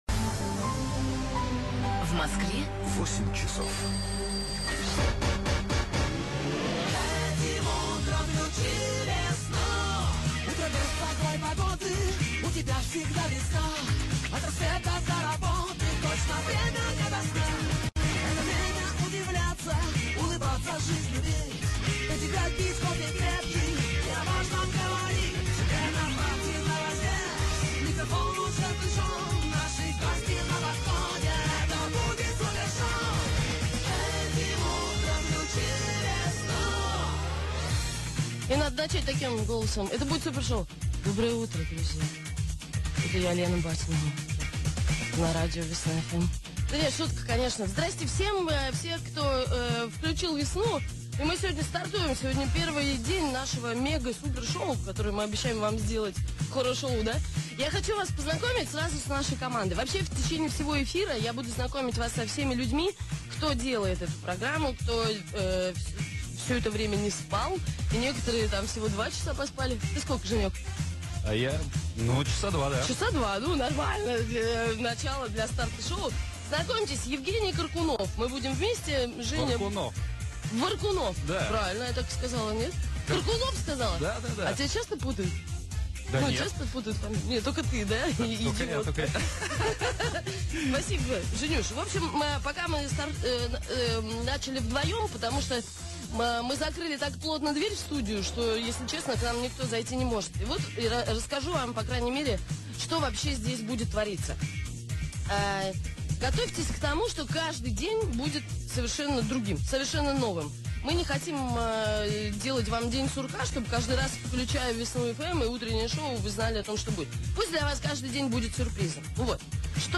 [ Скачать с сервера (8.59 Mb) ] 13 января 2014 года в московский эфир вышло новое утреннее шоу.
Музыка - 4 песни в час.
Хр.: 18'45" (фрагмент первого часа).